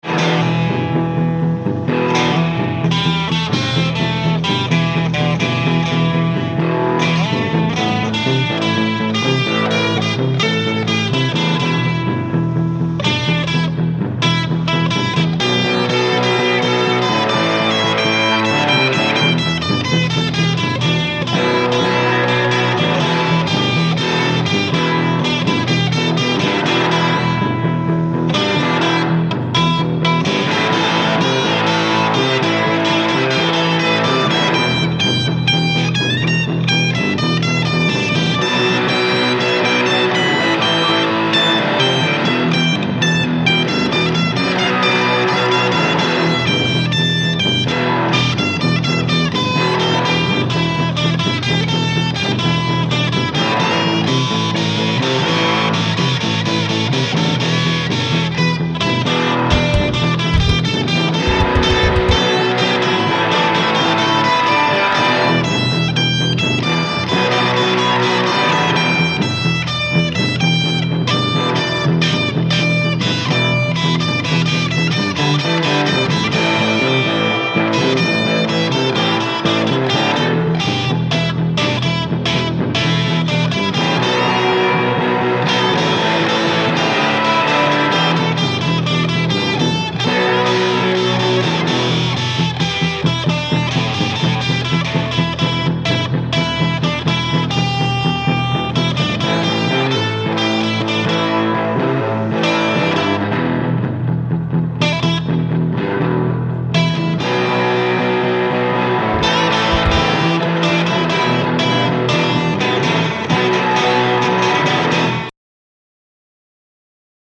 random blues jam
It wasn't spit and polish, but it wasn't too bad.